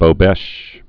(bō-bĕsh)